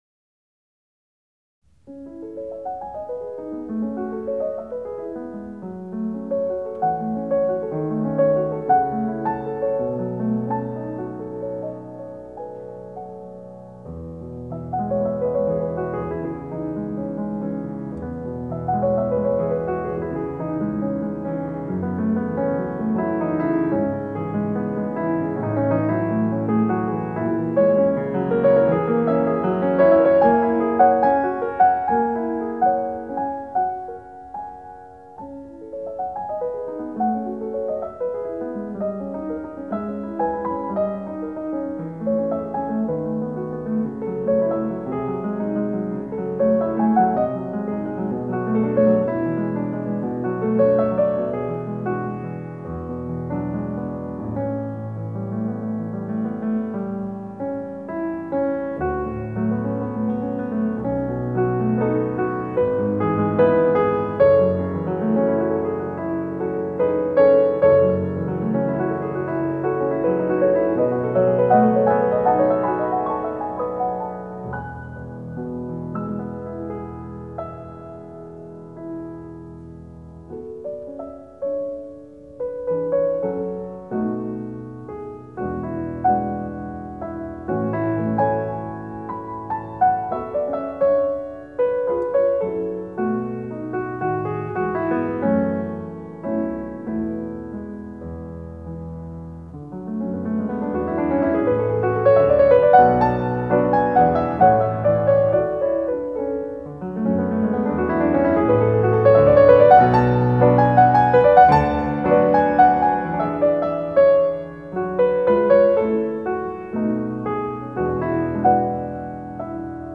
このピアノ曲では、右手と左手が異なったリズムを奏で、優美で洗練された世界観をつくりだしてる。